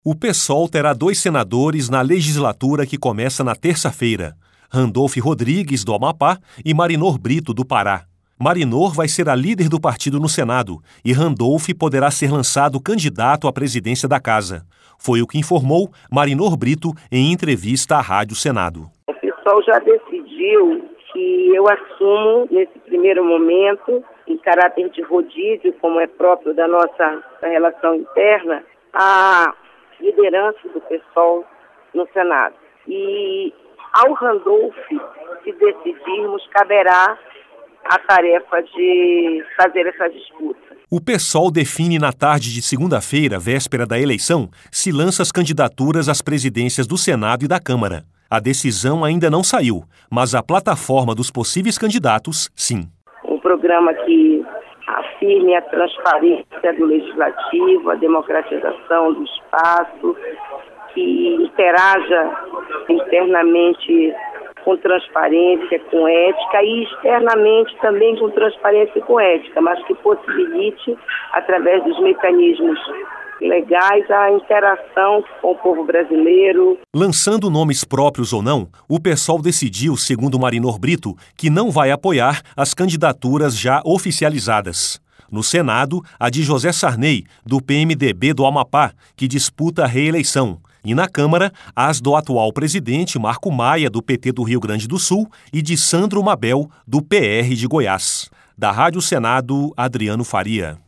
Foi o que informou Marinor Brito em entrevista à Rádio Senado.